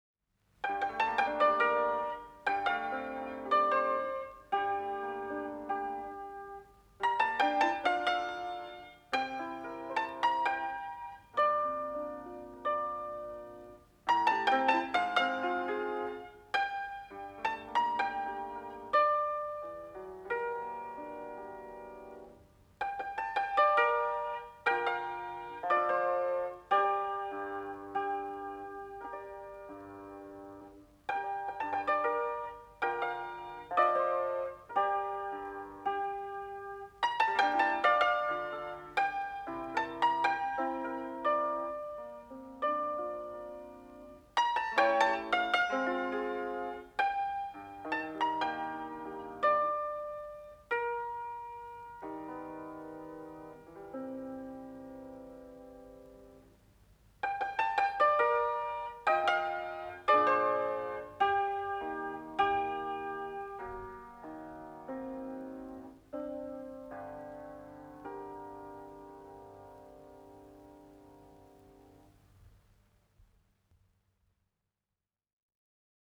cimbalom.mp3